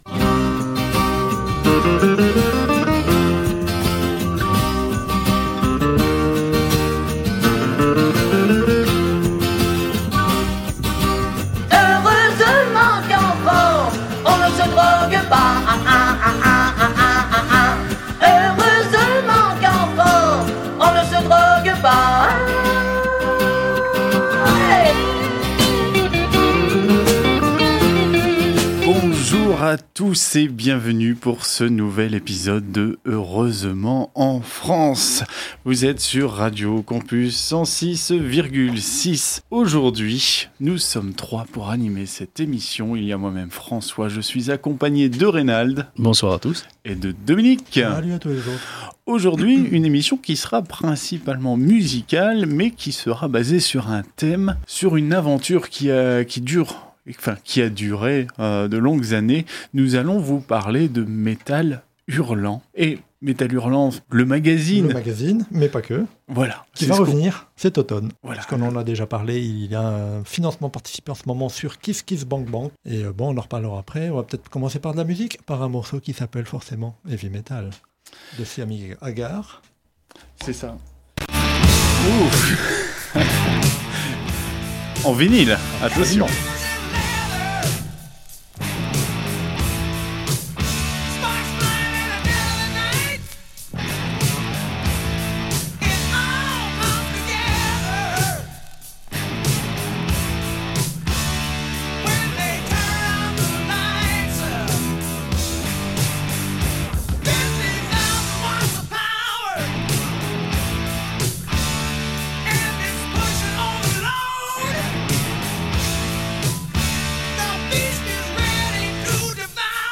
Au sommaire de cet épisode diffusé le 11 juillet 2021 sur Radio Campus 106.6 :